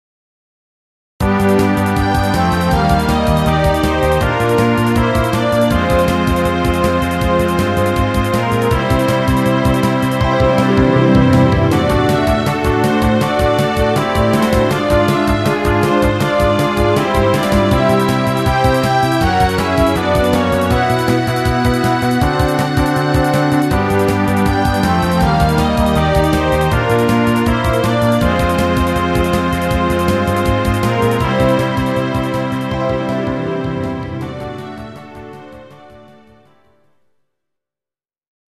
疾走感あふれる曲。